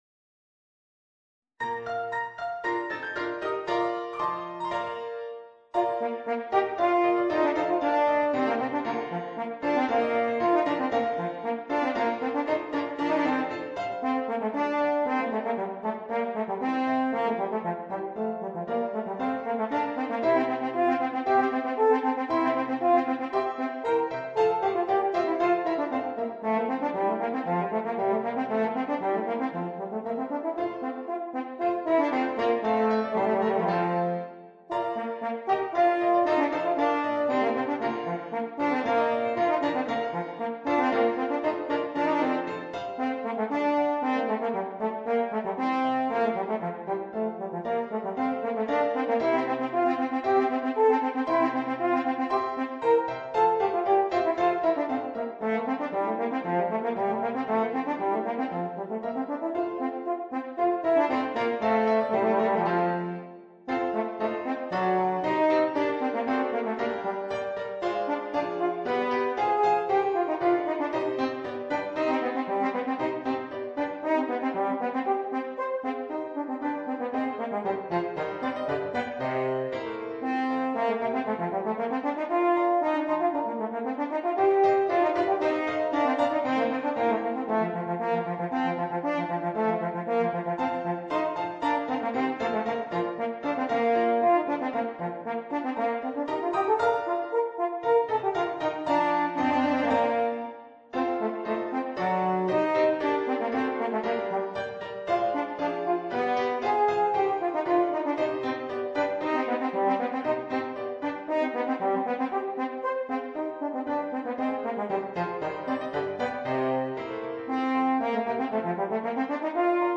Voicing: Horn and Piano